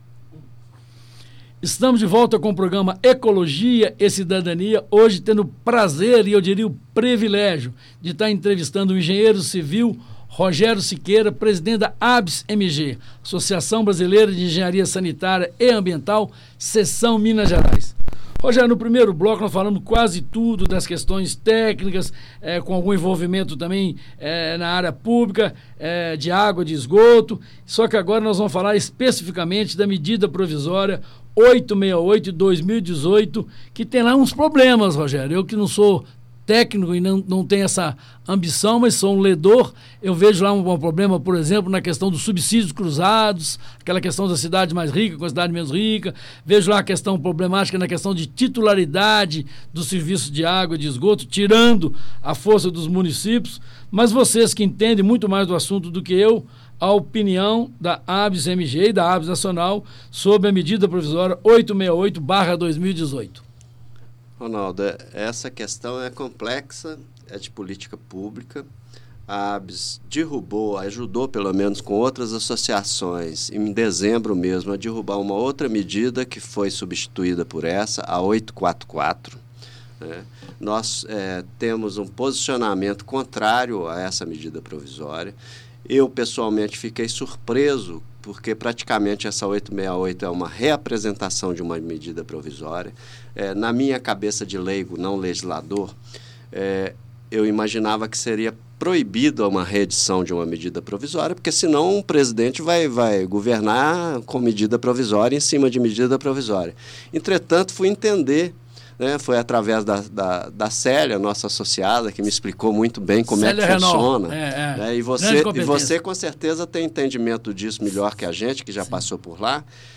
Ouça a entrevista: Bloco I Bloco II - MP868, Brumadin ho